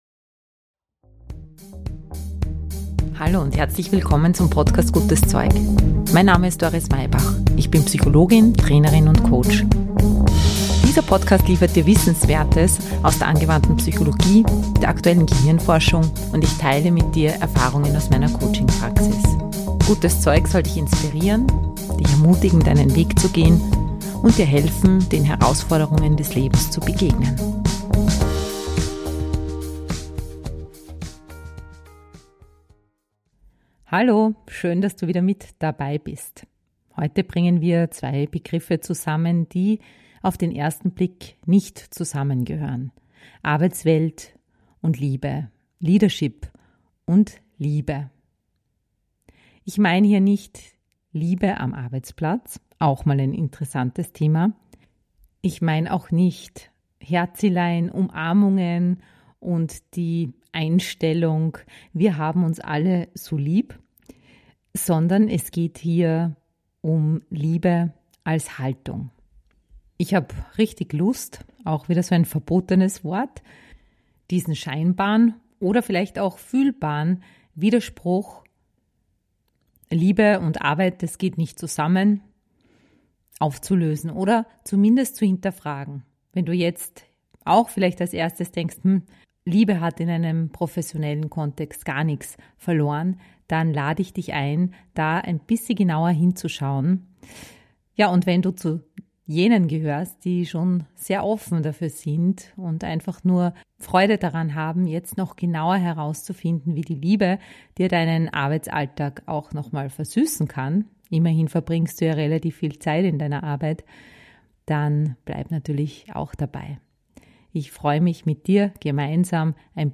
Am gemütlichen Küchentisch plaudern wir über "Gott und die Welt." - über das, was uns im Alltag beschäftigt und wie wir damit umgehen (könnten).
Wir haben viel gelacht und sogar die eine oder andere Träne vergossen.